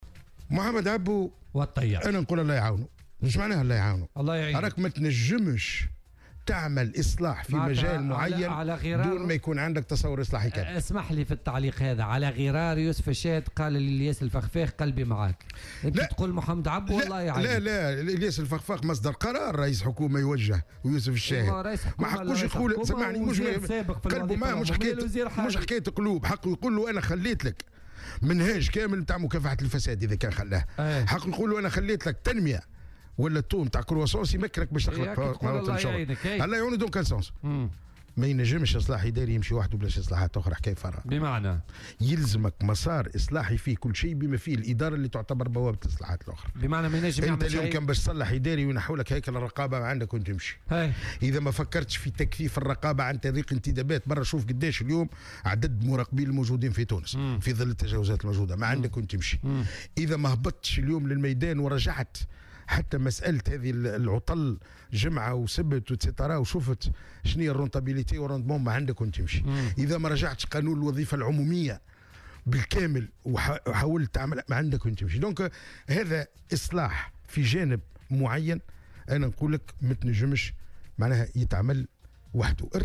وأضاف في مداخلة له اليوم في برنامج "بوليتيكا" أن المسار الإصلاحي يقتضي القيام بجملة من الإصلاحات الأخرى بالتوازي مع الإصلاح الإداري.